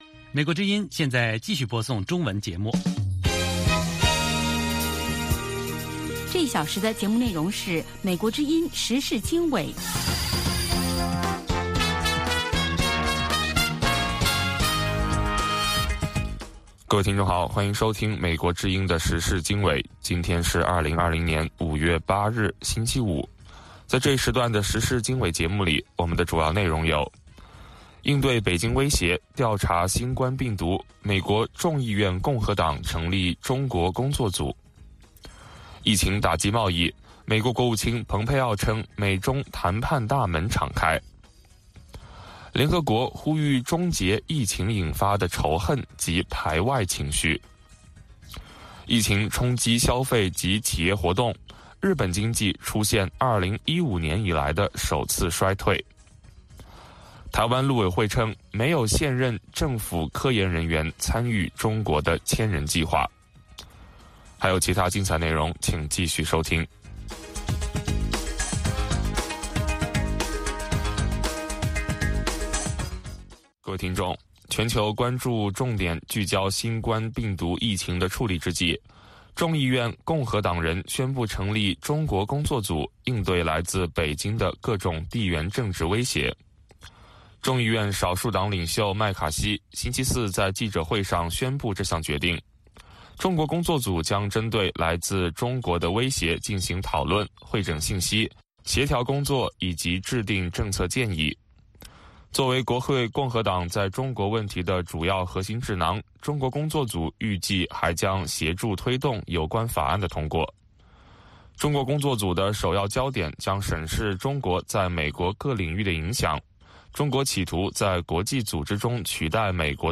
美国之音中文广播于北京时间每天晚上7-8点播出《时事经纬》节目。《时事经纬》重点报道美国、世界和中国、香港、台湾的新闻大事，内容包括美国之音驻世界各地记者的报道，其中有中文部记者和特约记者的采访报道，背景报道、世界报章杂志文章介绍以及新闻评论等等。